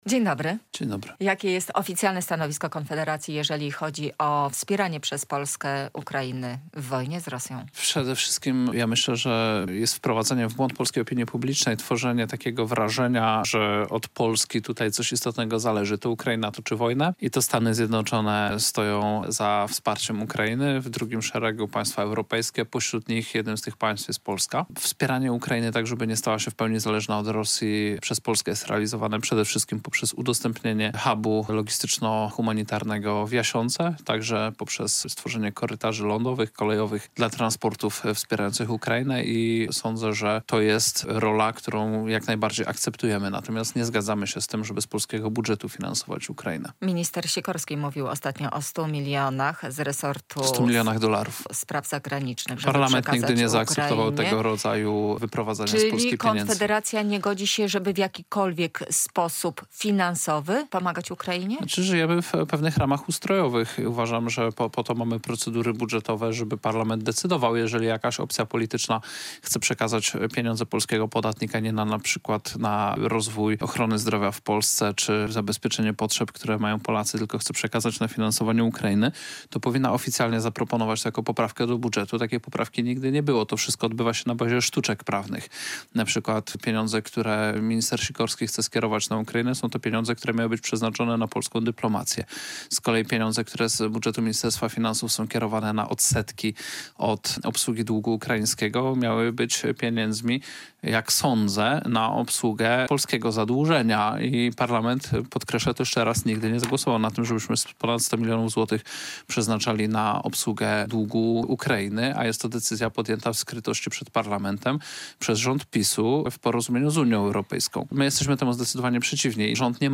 Radio Białystok | Gość | Krzysztof Bosak - wicemarszałek Sejmu